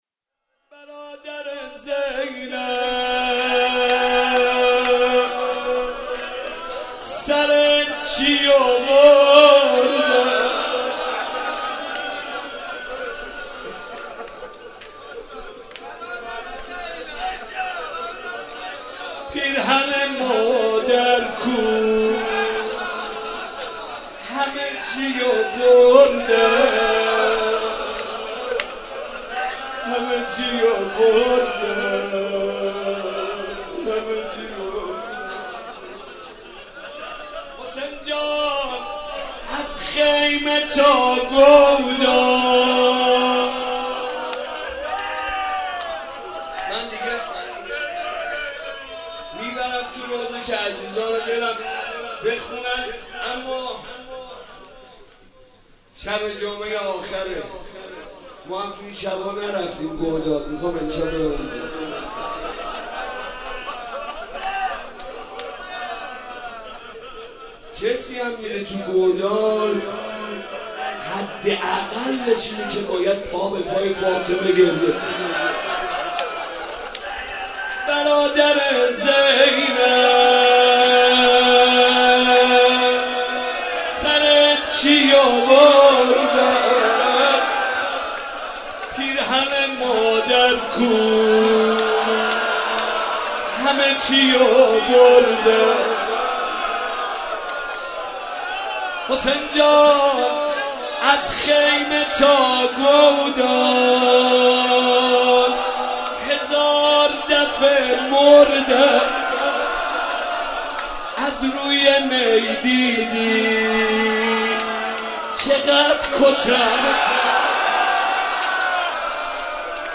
روضه
مناسبت : شب بیست و هشتم رمضان